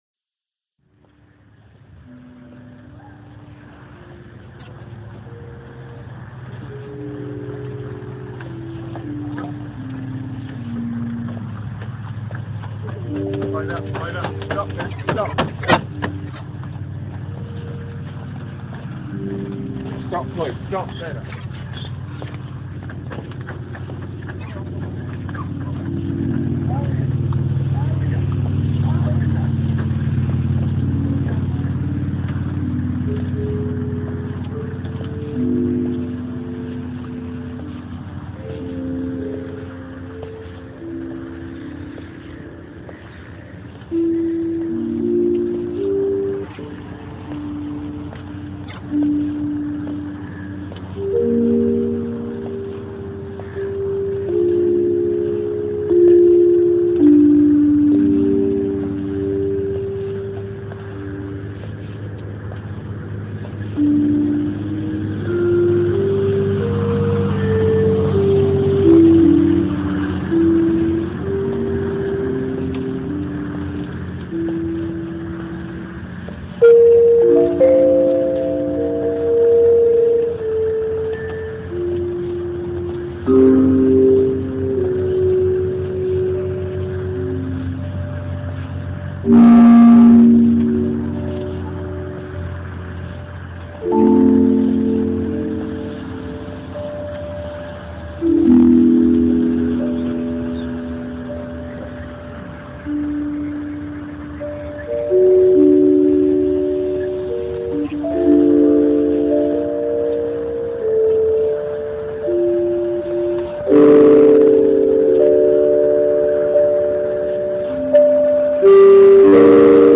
A stroll along the GASP! Boardwalk